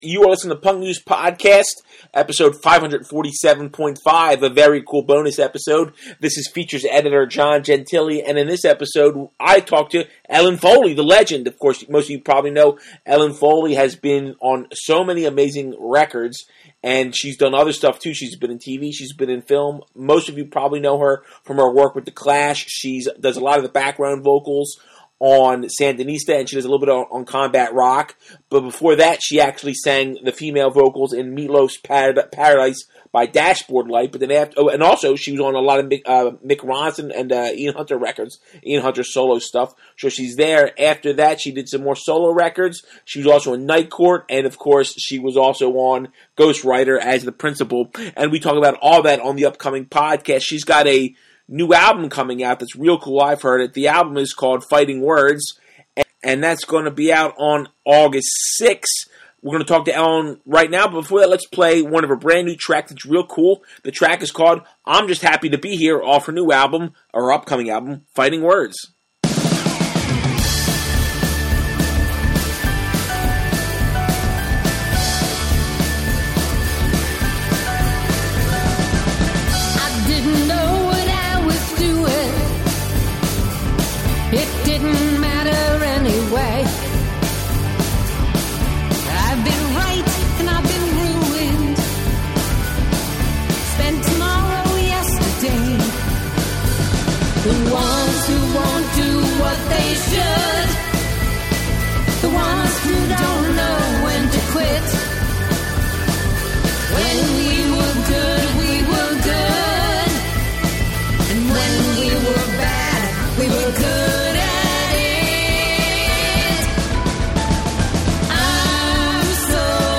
#547.5 - An interview with Ellen Foley